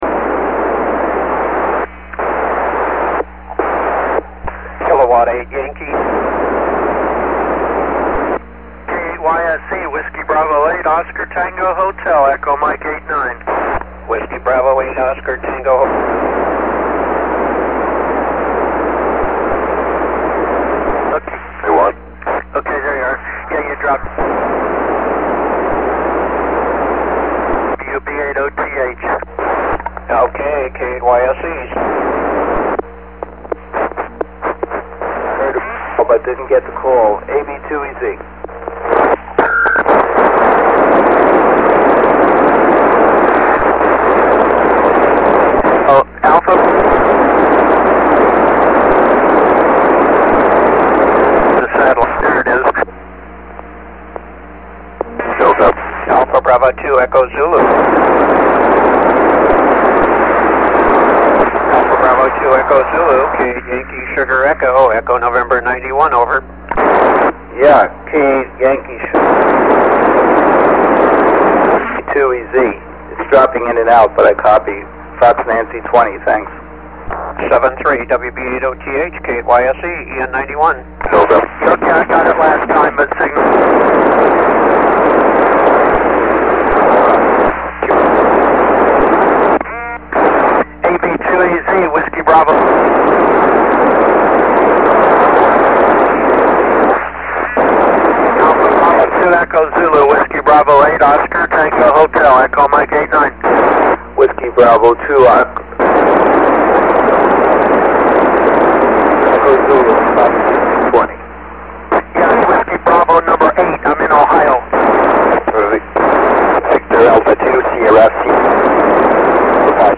An audio recording of the SO-67 Eastern USA pass
is a little loud because I had the TS2000 in the
repeater still seems to turn off most of the time
after a few seconds of transmission.  Lots of
carriers mixing and most likely affecting the
The received signal level was very strong during the entire